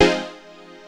HOUSE 11-R.wav